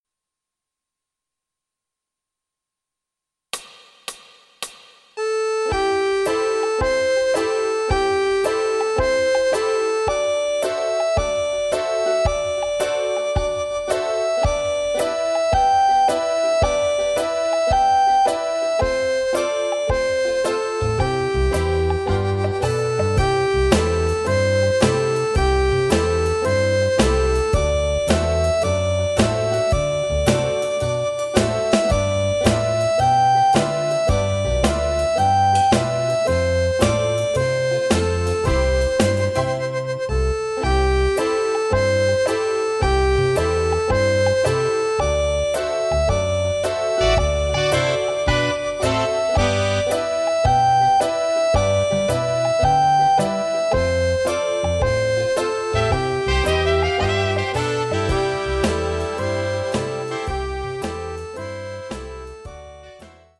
(Misolidia)